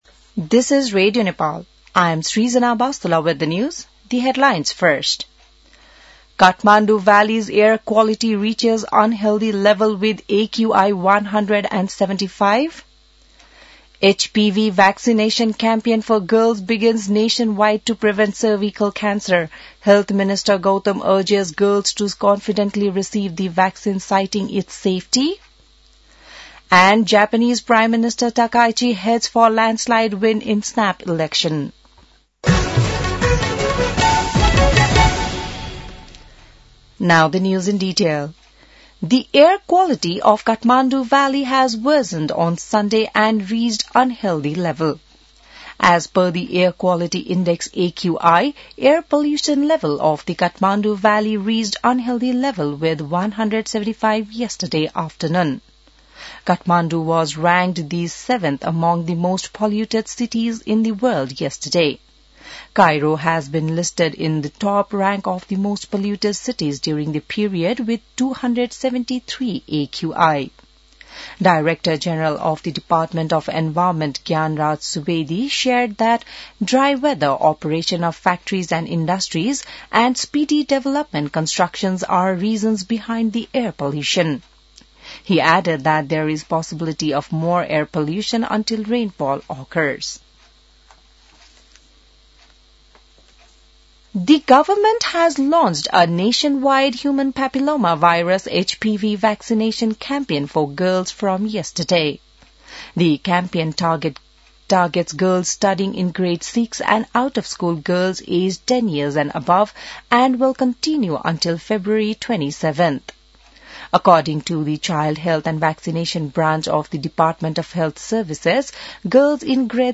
बिहान ८ बजेको अङ्ग्रेजी समाचार : २६ माघ , २०८२